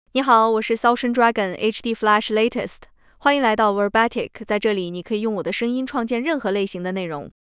Xiaochen Dragon HDFlash Latest is a female AI voice for Chinese (Mandarin, Simplified).
Voice sample
Listen to Xiaochen Dragon HDFlash Latest's female Chinese voice.
Female